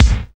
SWING BD 11.wav